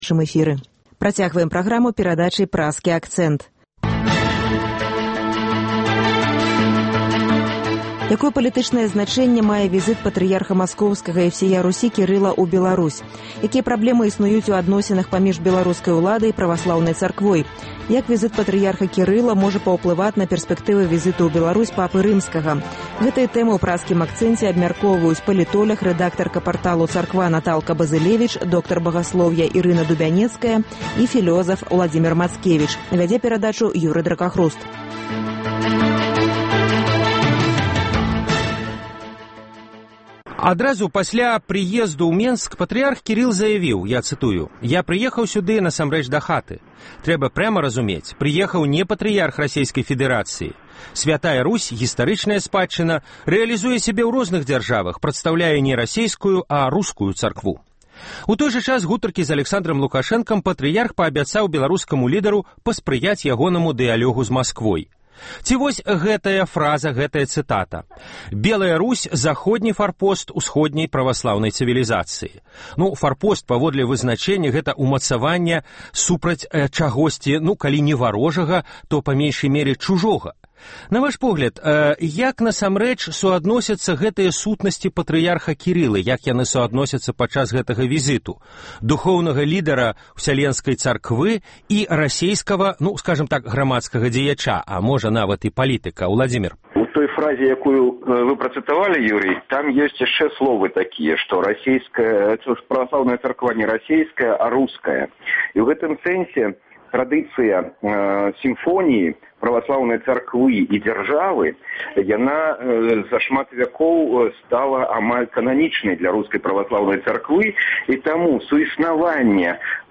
Якое палітычнае значэньне мае візыт Патрыярха Маскоўскага і Ўсея Русі Кірыла ў Беларусь? Якія праблемы існуюць у адносінах паміж беларускай уладай і праваслаўнай царквой? Як візыт Патрыярха Кірыла можа паўплываць на пэрспэктывы візыту ў Беларусь Папы Рымскага? Гэтыя тэмы ў “Праскім акцэнце” абмяркоўваюць